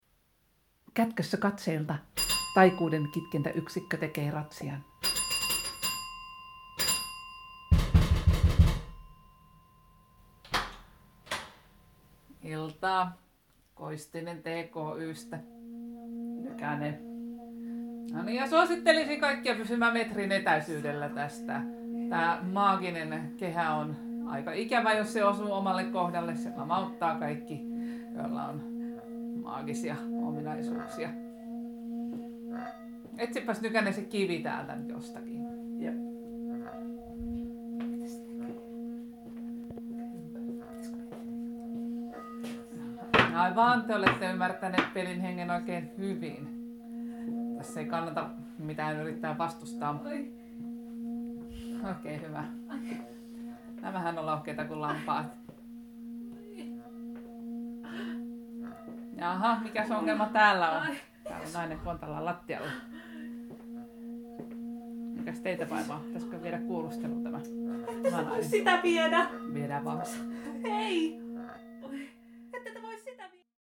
He käyttivät apunaan lamauttavaa, maagista ääntä, joka saatiin aikaan tiibetiläisellä soivalla kulholla.
Kuuntele, miten Taikuudenkitkentäyksikkö lamauttaa maageja. Äänite ei ole suoraan pelistä, vaan se mallintaa pelin tapahtumia ja erityisesti lamauttavaa taikamaljaa.
Kuvassa kulhoa soitetaan kiertämällä sen reunaa kapulalla.